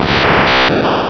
pokeemmo / sound / direct_sound_samples / cries / abra.wav